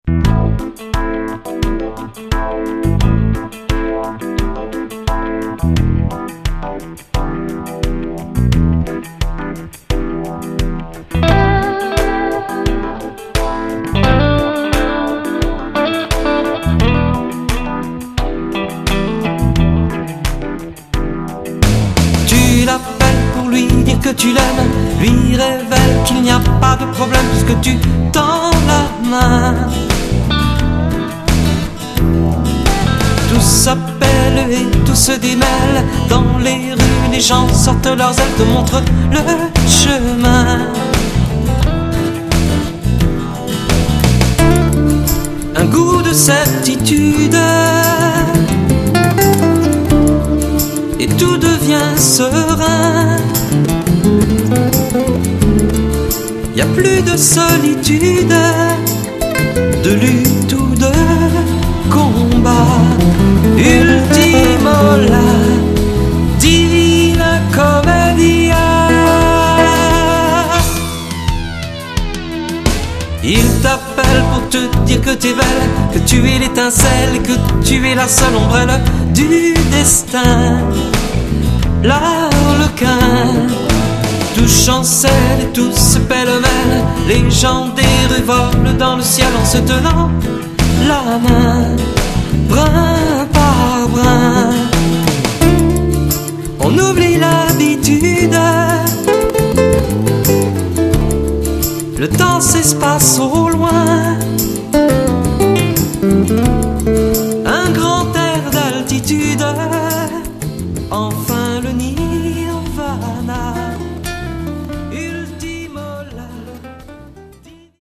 au studio l'hiver 2005